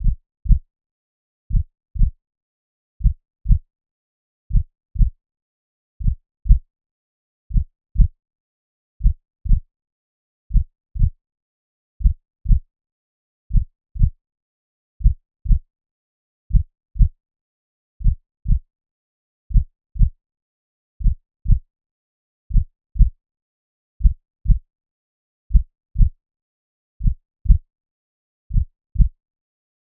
Slow Heartbeat Sound Effect Free Download
Slow Heartbeat